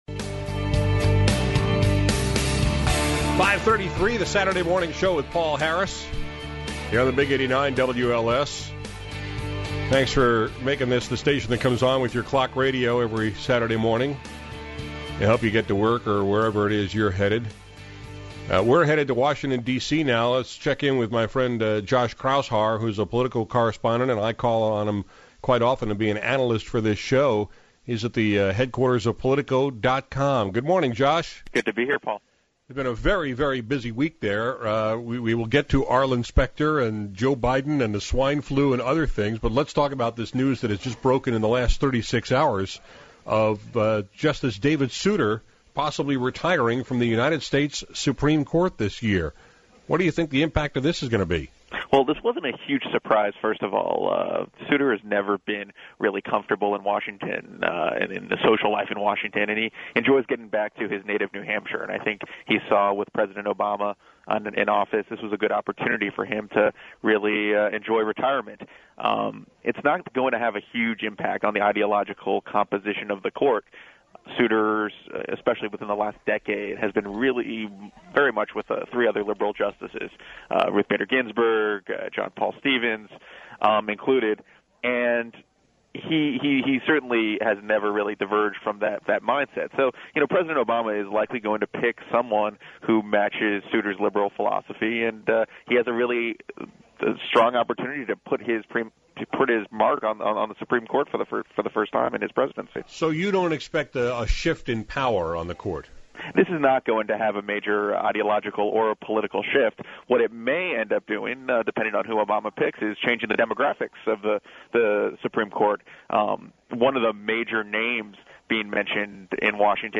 This morning on WLS/Chicago